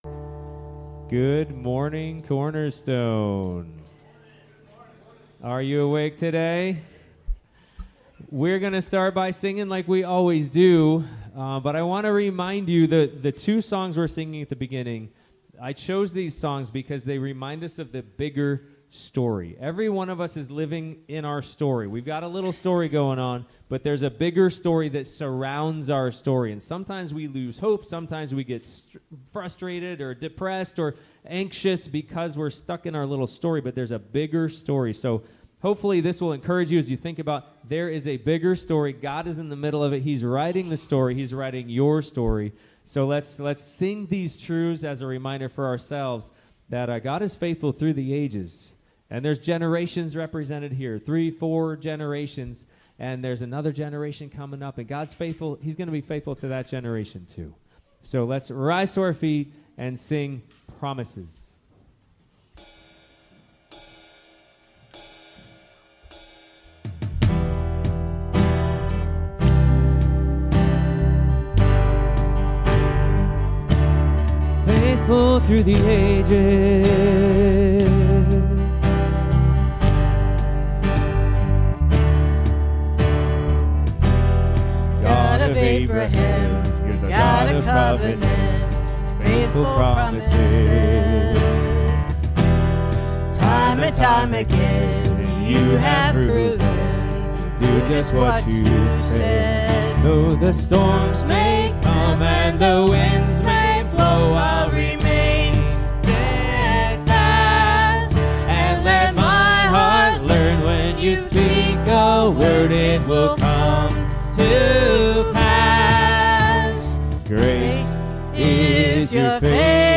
Relationships Passage: Ephesians 5:15-21 Service Type: Sunday Morning « The Artist & the Art Nobody wants to submit